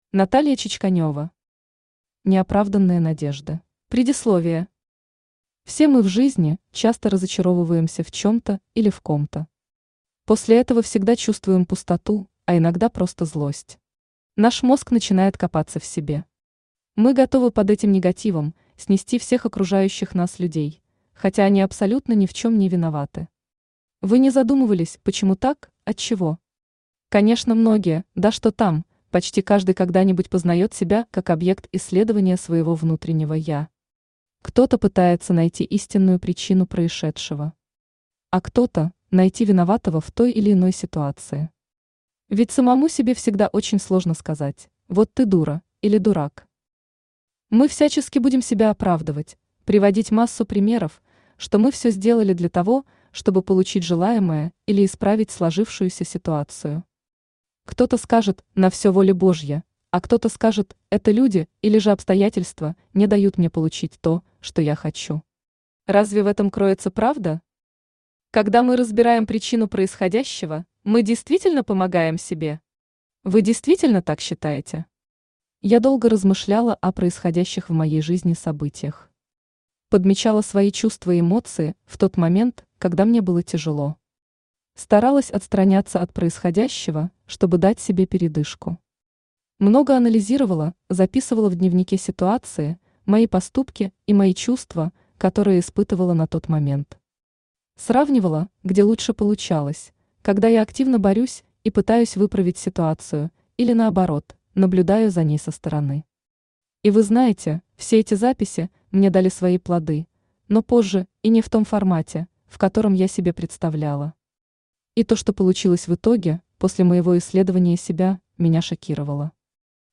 Аудиокнига Неоправданные надежды | Библиотека аудиокниг